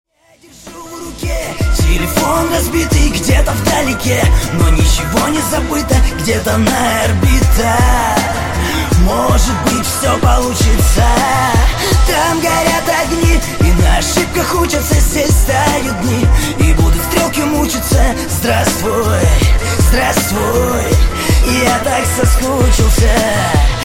• Качество: 128, Stereo
русский рэп
пацанские
шансон